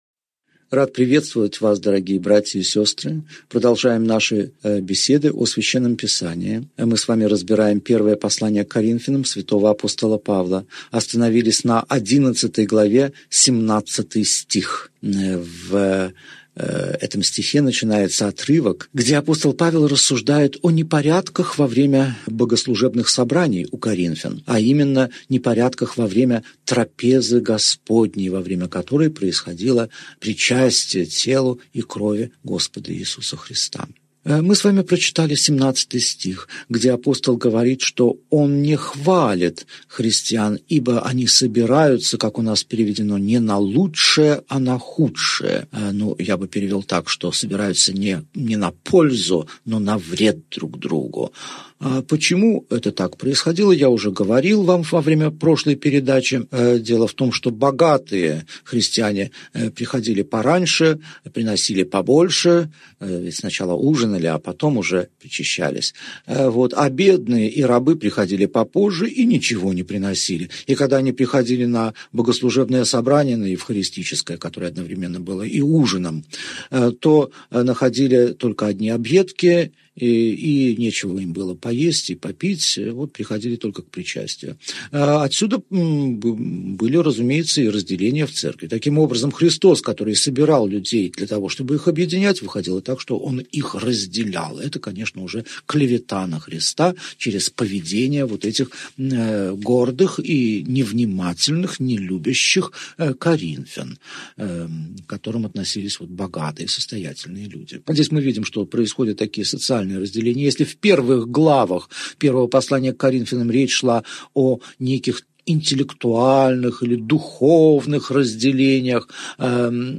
Аудиокнига Беседа 30. Первое послание к Коринфянам. Глава 11 | Библиотека аудиокниг